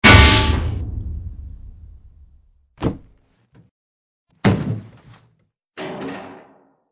AA_drop_safe.ogg